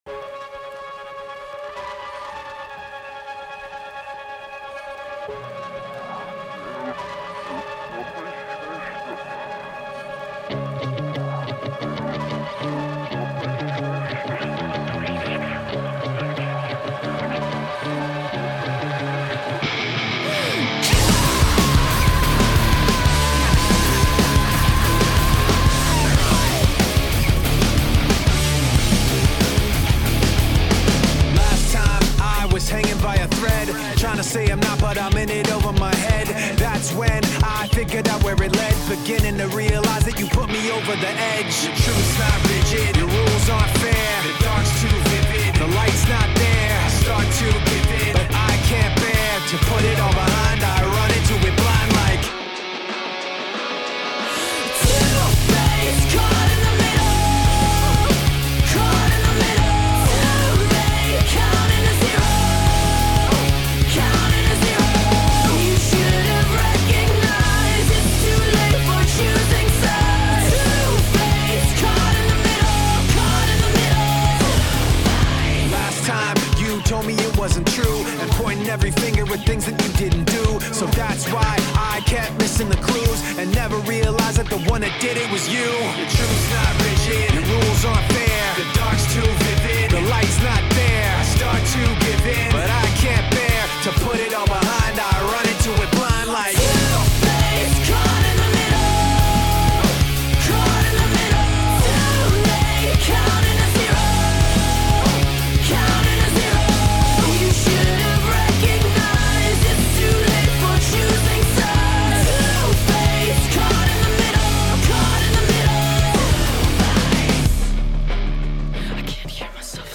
alternative metal nu metal